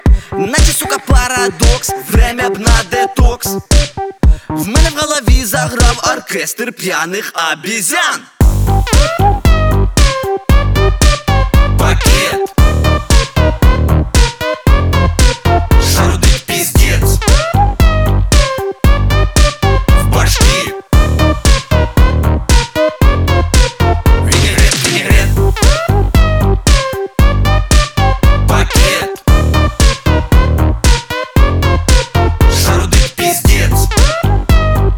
Pop Hip-Hop Rap
Жанр: Хип-Хоп / Рэп / Поп музыка / Украинские